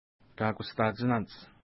Pronunciation: ka:kusta:tʃina:nts
Pronunciation